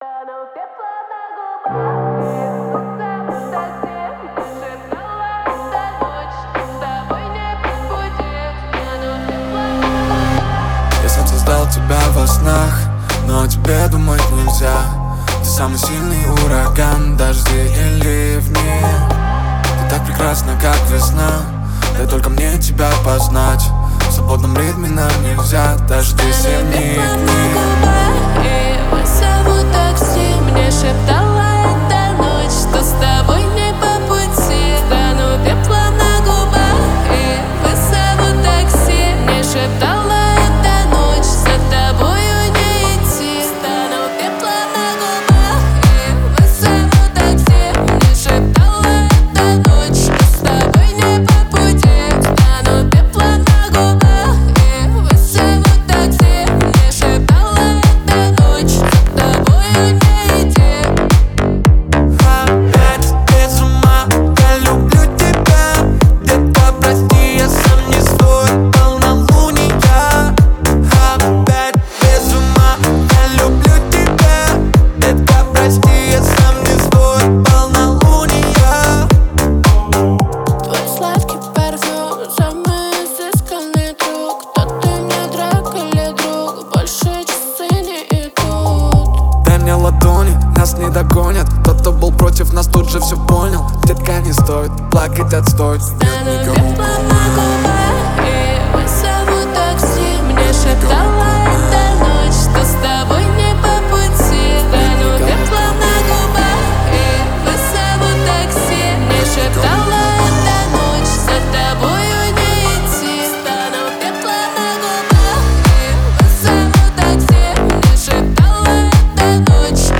Клубные русские песни